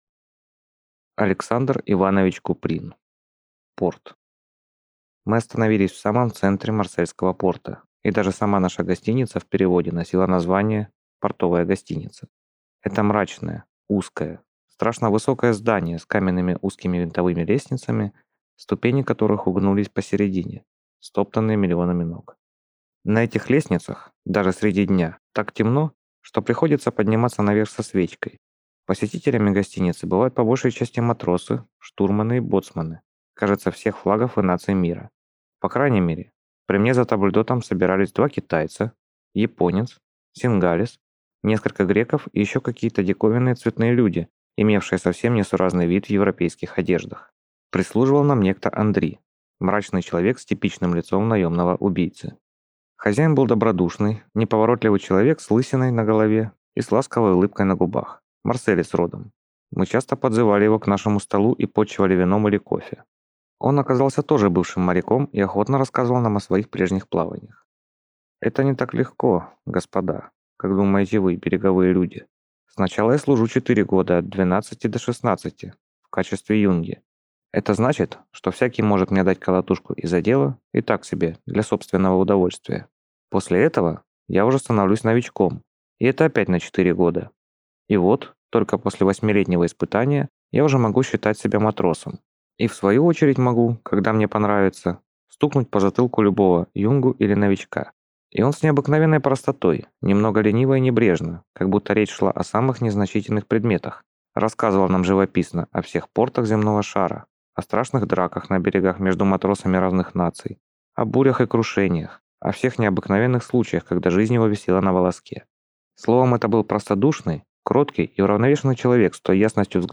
Аудиокнига Порт | Библиотека аудиокниг